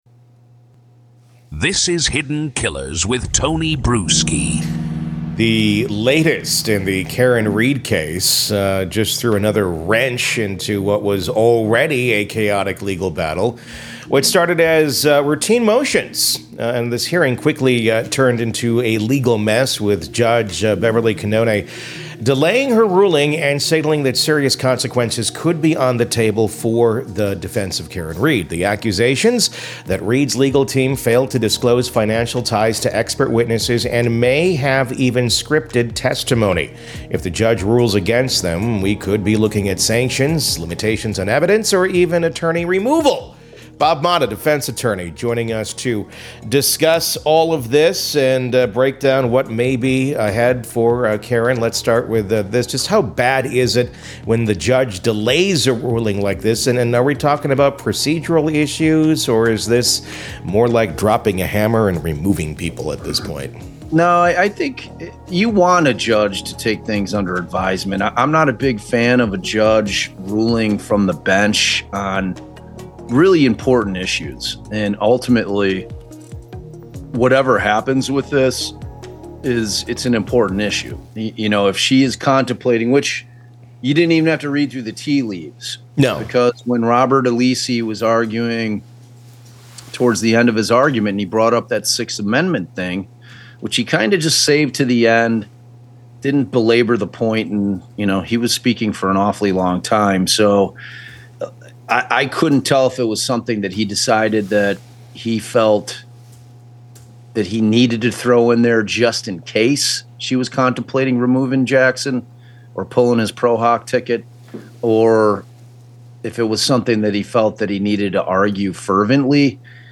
Defense attorney